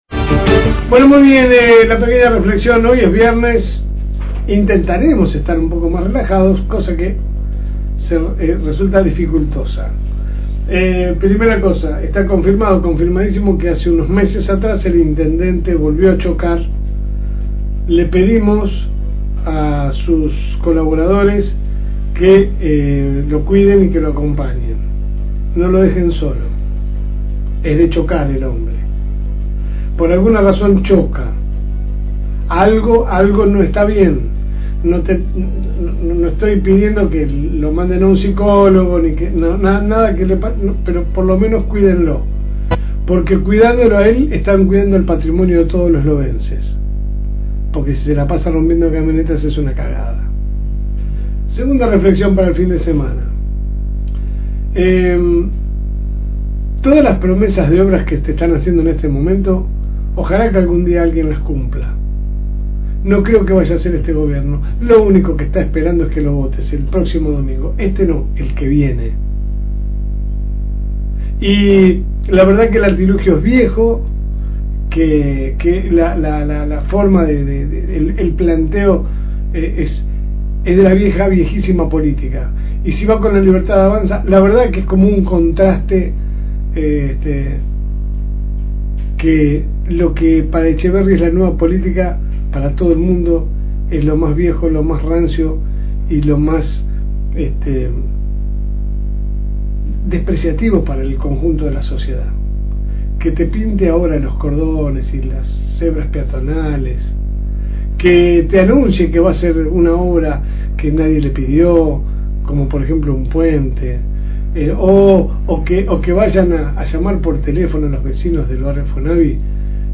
editorial
En su Programa LSM que sale de lunes a viernes de 10 a 12 hs por el aire de la FM Reencuentro 102.9